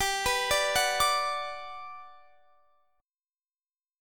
Listen to GM7 strummed